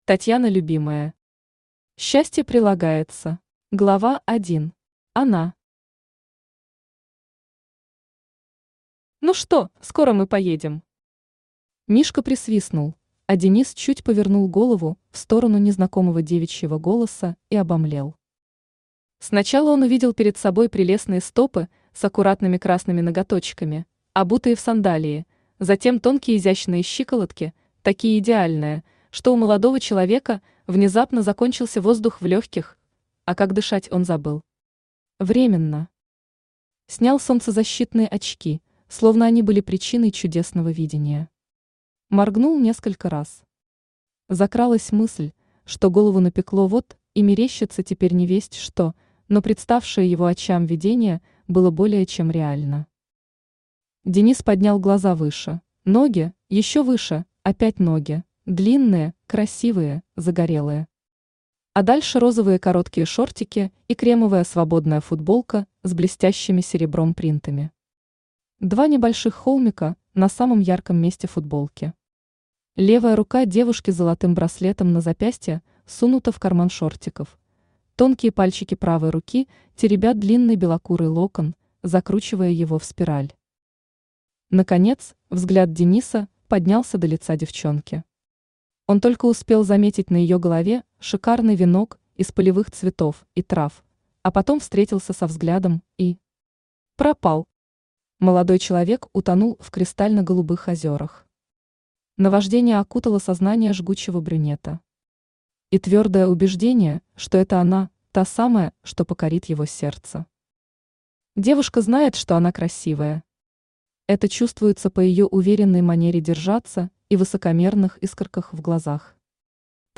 Аудиокнига Счастье прилагается | Библиотека аудиокниг
Aудиокнига Счастье прилагается Автор Татьяна Любимая Читает аудиокнигу Авточтец ЛитРес.